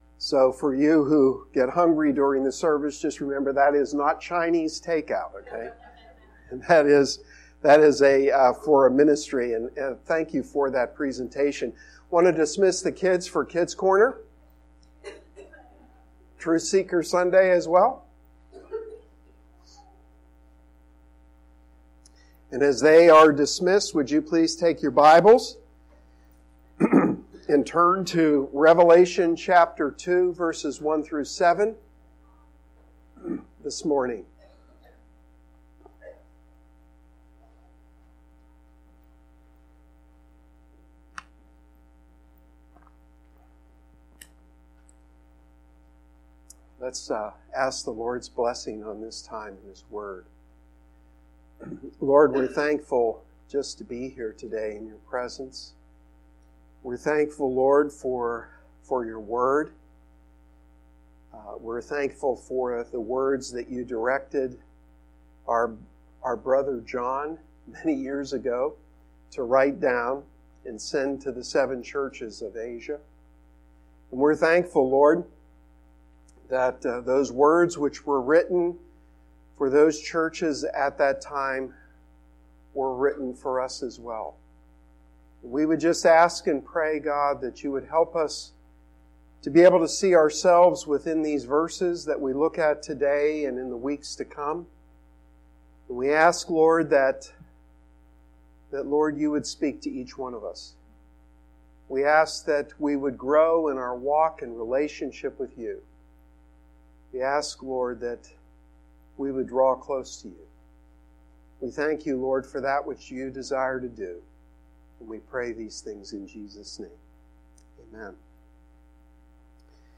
Sermon-1-27-19.mp3